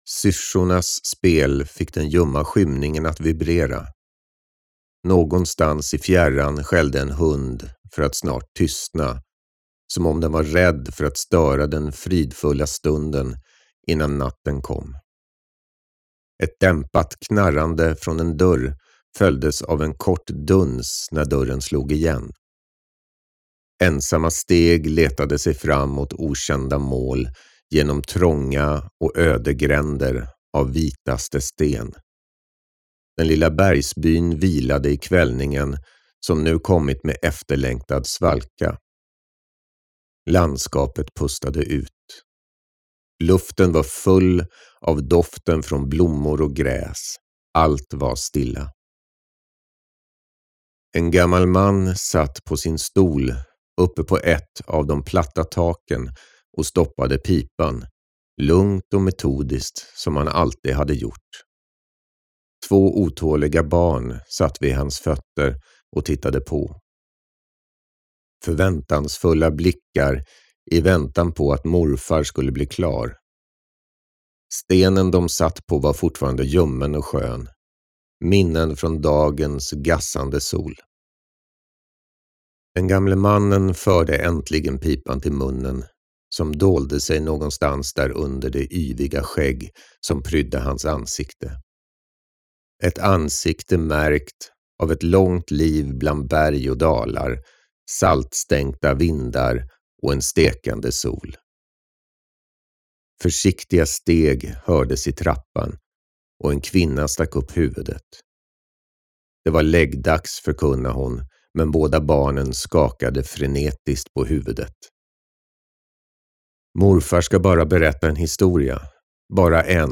Produktionen av ljudboksversionen av Minotauros Tårar är igång.